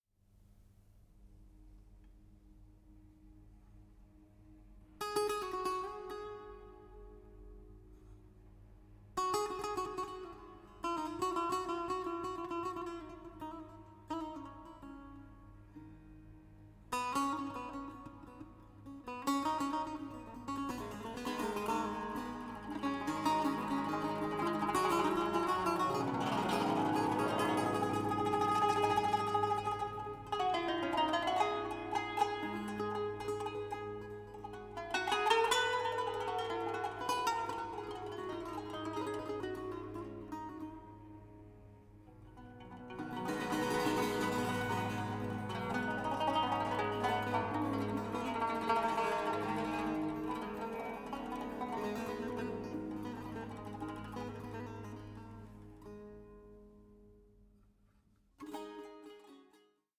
BAROQUE MEETS THE ORIENT: MUSIC AS INTERCULTURAL DIALOGUE